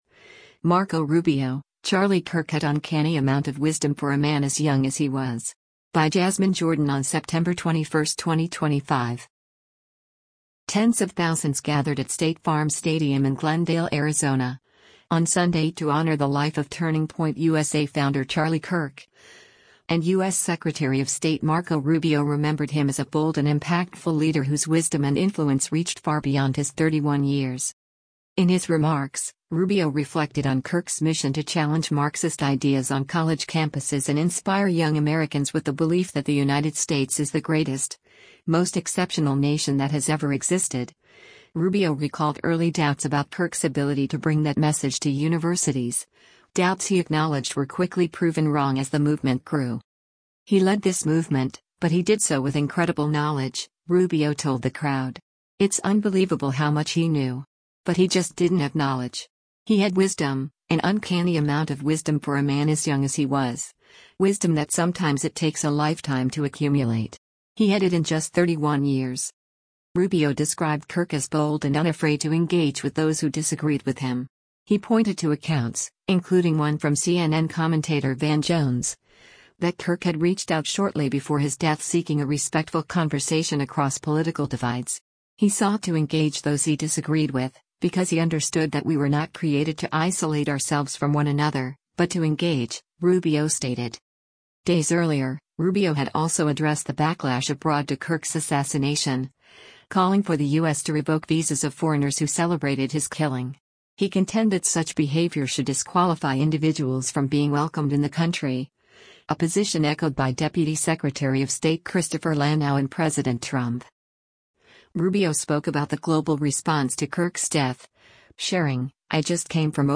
GLENDALE, ARIZONA - SEPTEMBER 21: Secretary of State Marco Rubio speaks during the memoria
Tens of thousands gathered at State Farm Stadium in Glendale, Arizona, on Sunday to honor the life of Turning Point USA founder Charlie Kirk, and  U.S. Secretary of State Marco Rubio remembered him as a bold and impactful leader whose wisdom and influence reached far beyond his 31 years.